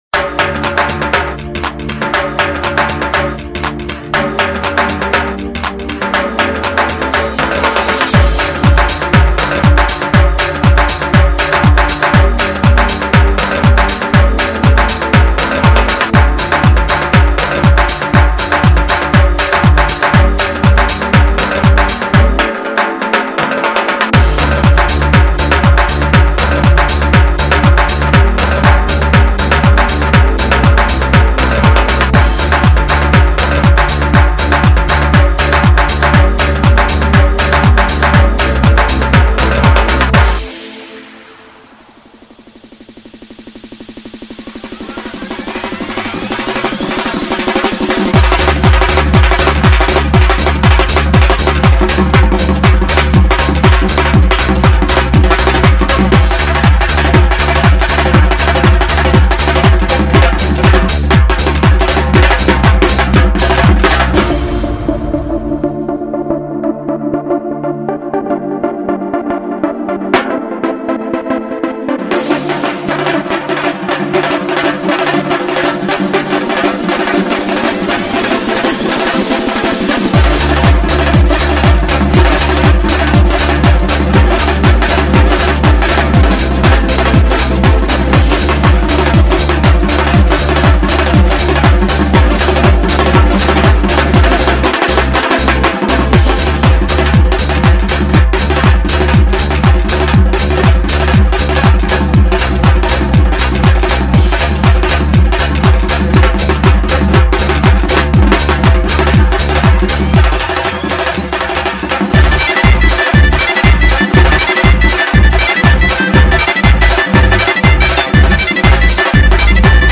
• Жанр: Лезгинка музыка